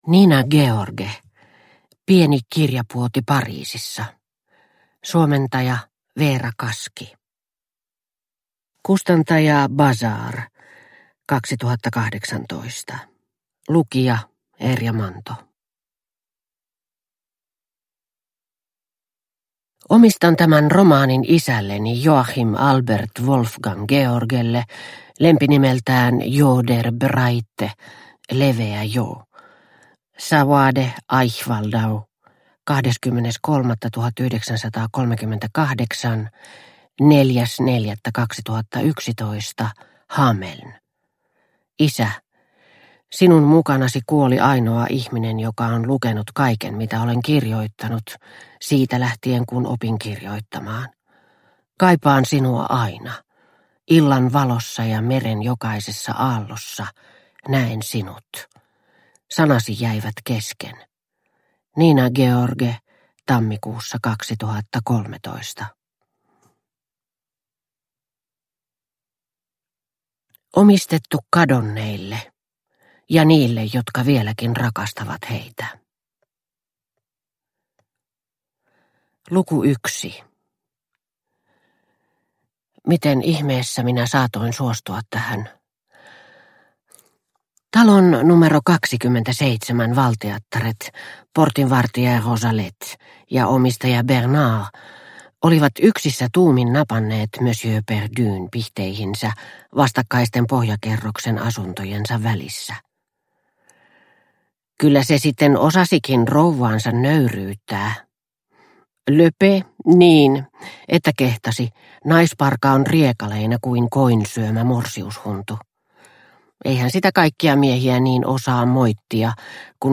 Pieni kirjapuoti Pariisissa – Ljudbok – Laddas ner